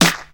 • Good Steel Snare Drum F Key 320.wav
Royality free steel snare drum tuned to the F note. Loudest frequency: 3285Hz
good-steel-snare-drum-f-key-320-KTC.wav